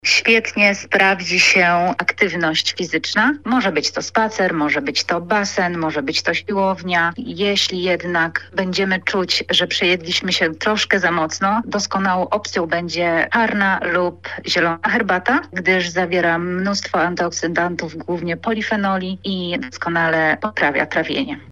O tym, jak sobie z tym poradzić, mówi dietetyk